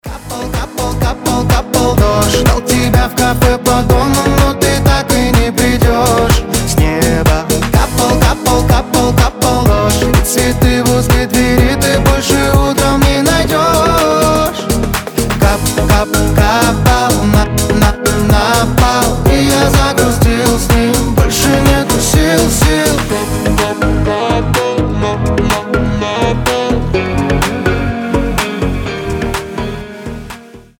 • Качество: 320, Stereo
мужской голос
ритмичные